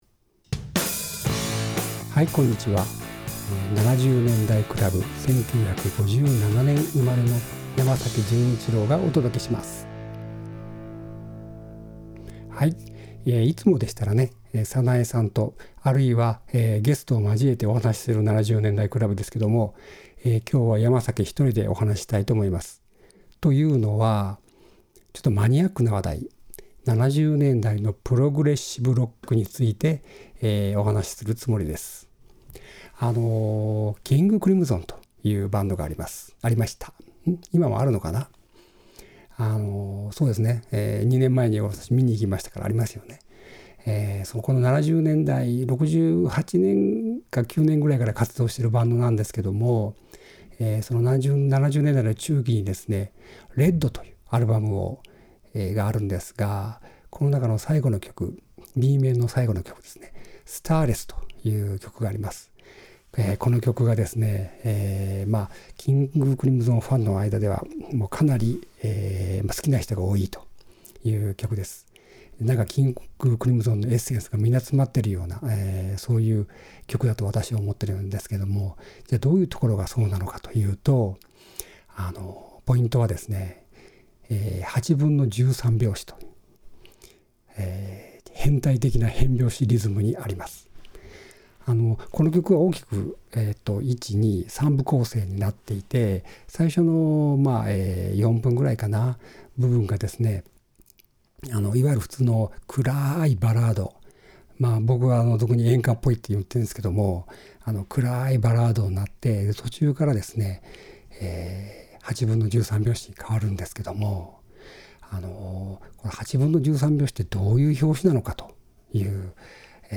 自分でベースを弾いて変拍子を解説しています。